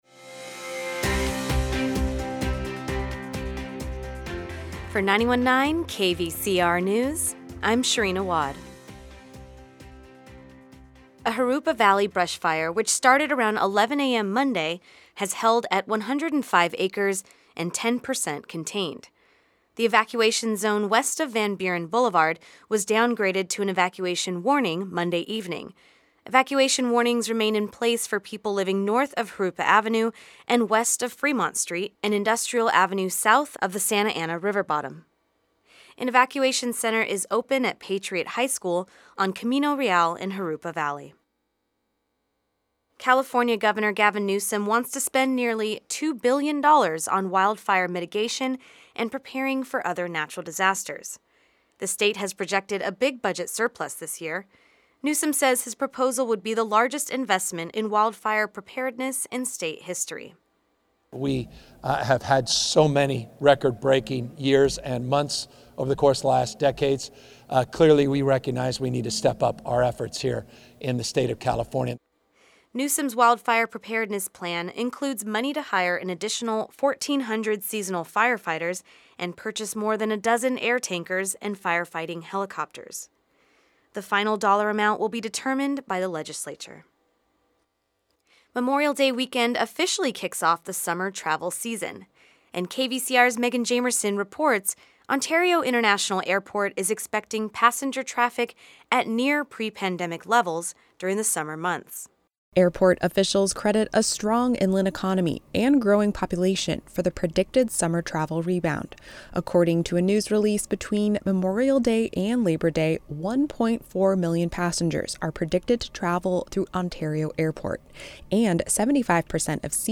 KVCR News has your daily news rundown at lunchtime.